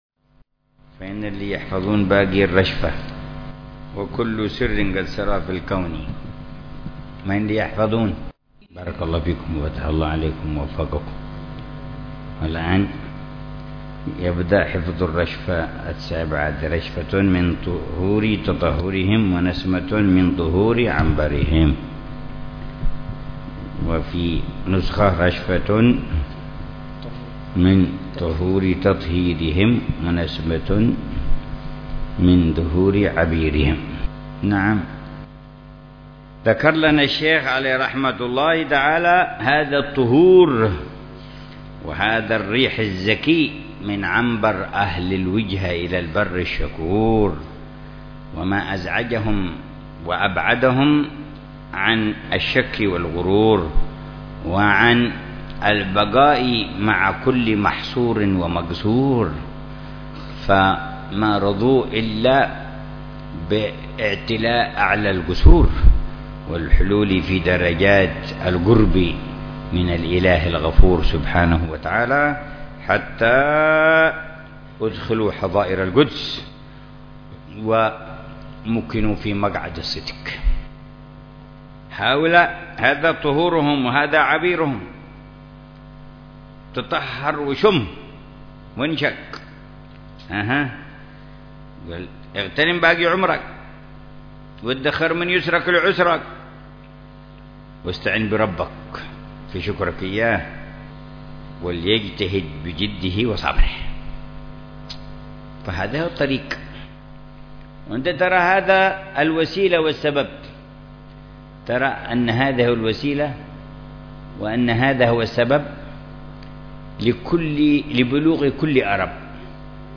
رشفات أهل الكمال ونسمات أهل الوصال - الدرس الستون
شرح الحبيب عمر بن محمد بن حفيظ لرشفات أهل الكمال ونسمات أهل الوصال.